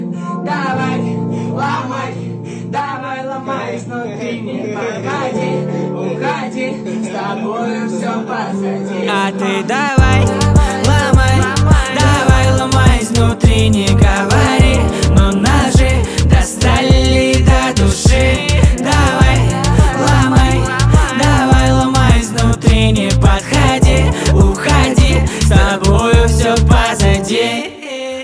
• Качество: 320, Stereo
мужской вокал
красивый мужской голос
русский рэп